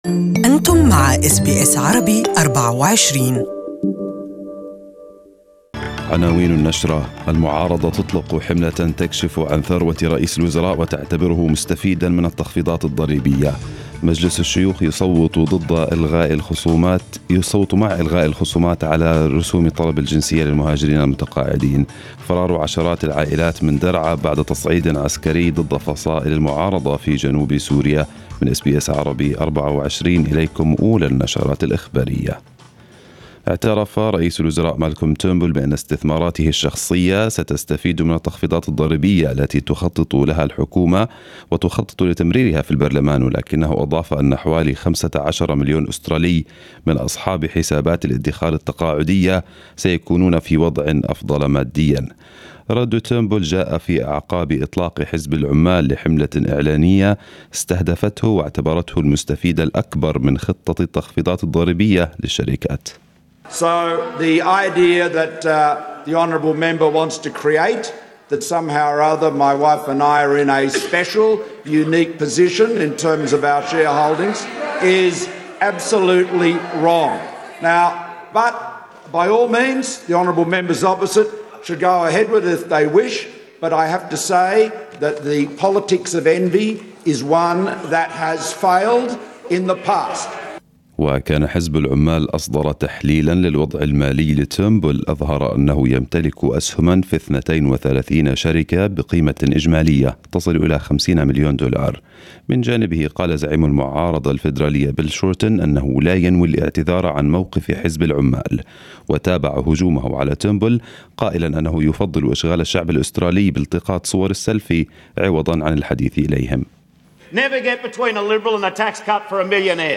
Arabic News Bulletin 26/06/2018